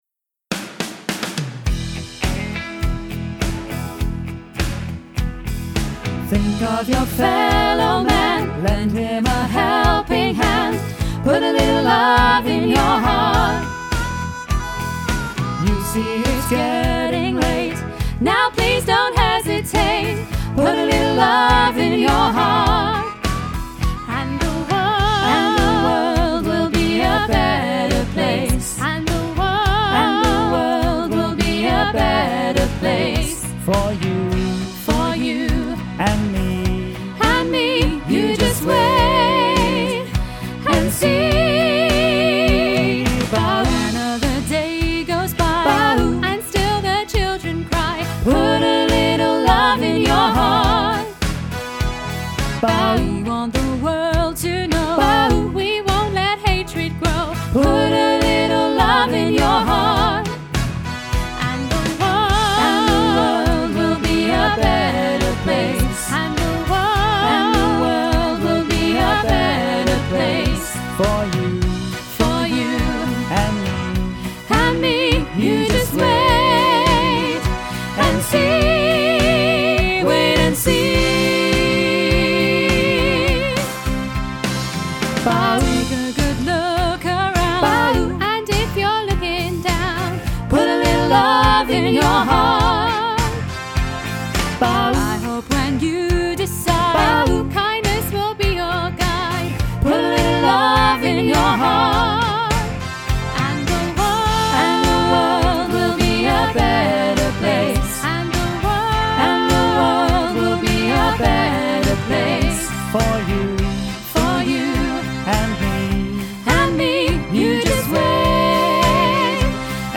Training Tracks for Put a Little Love in Your Heart
4-full-mix-put-a-little-love-in-your-heart.mp3